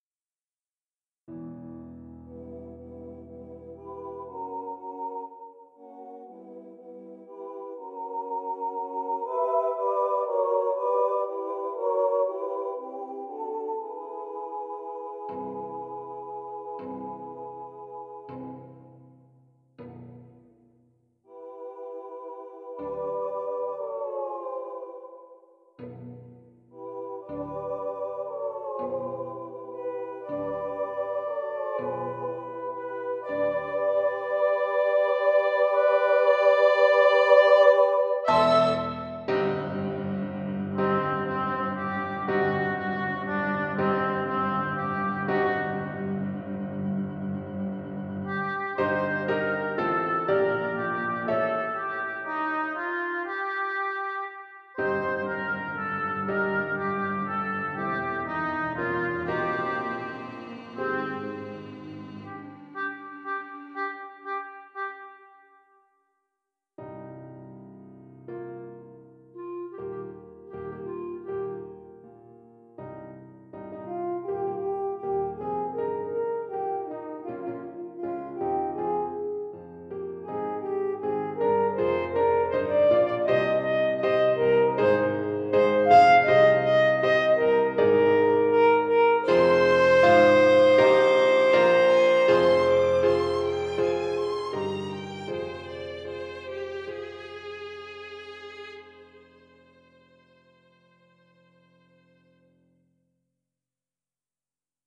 All All the Songs (as a zip file) 1 Introduction (Chorus) 2 The Greatest Marketeer (Jesse, Other Boys, Chorus) 3 A Muddied Path (Chorus) 4 A Bad Mix (Chorus) 5 What just happened?